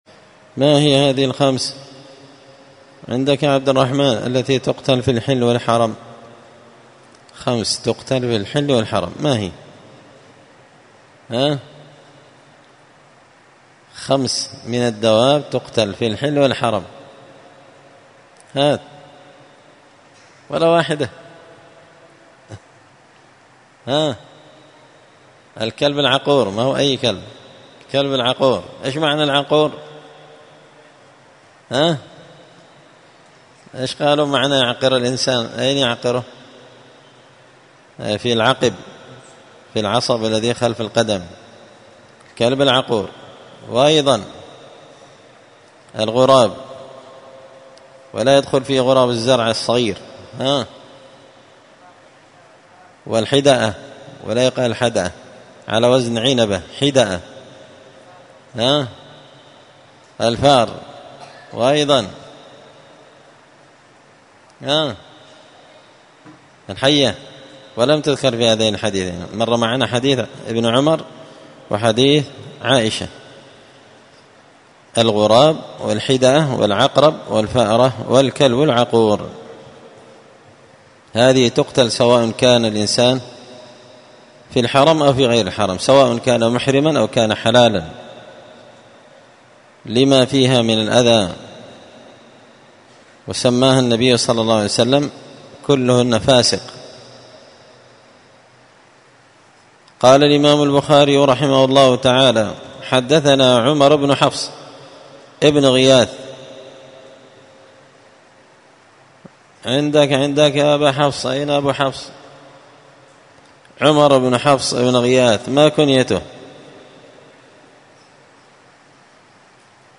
الثلاثاء 4 ربيع الأول 1445 هــــ | 5-كتاب جزاء الصيد، الدروس، شرح صحيح البخاري | شارك بتعليقك | 70 المشاهدات